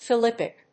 音節phi・lip・pic 発音記号・読み方
/fɪlípɪk(米国英語)/
philippic.mp3